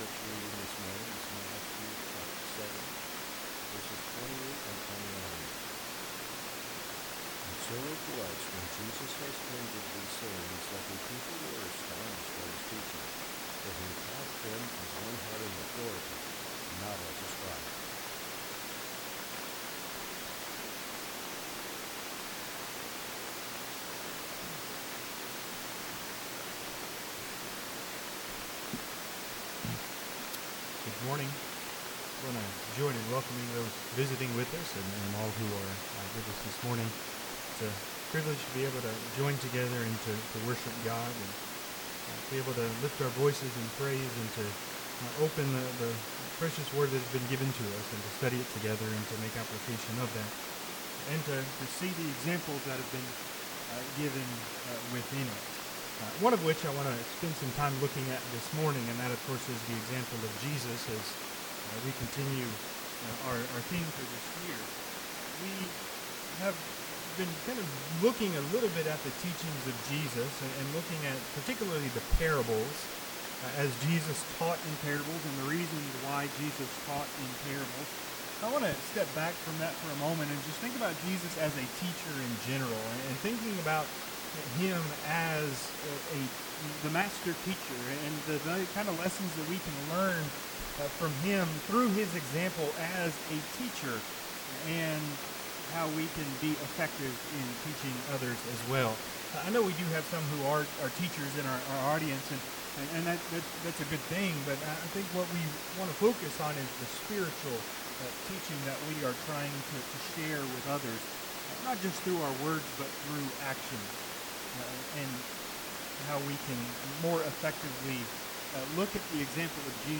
Matthew 7:28-29 Service Type: Sunday AM Topics